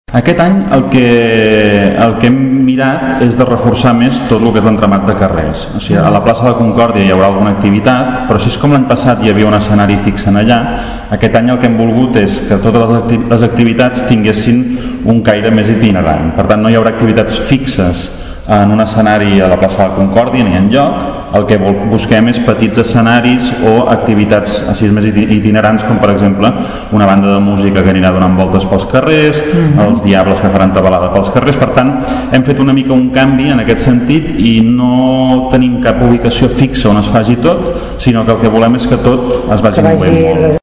La novetat és que no hi haurà un escenari fix i totes les actuacions seran itinerants. Ho explica Marc Unió, regidor de Promoció Econòmica de l’Ajuntament de Tordera.